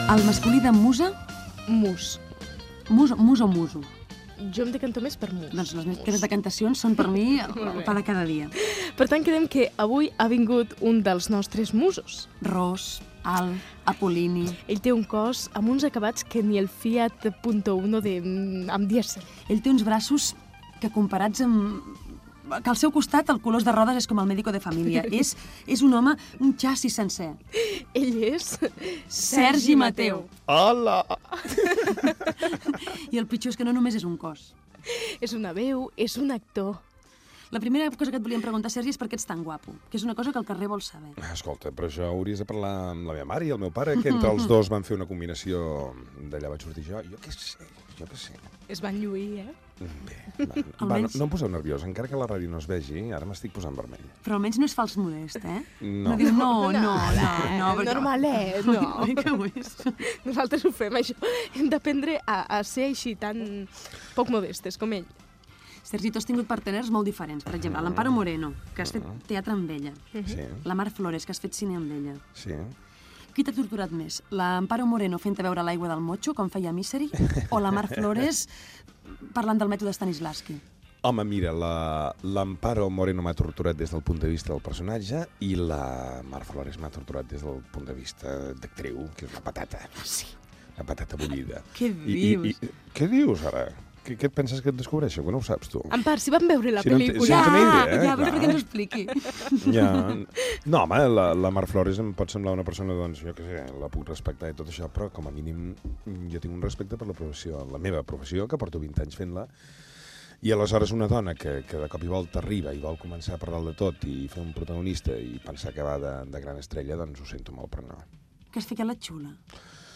Fragment d'una entrevista a l'actor Sergi Mateu.
Entreteniment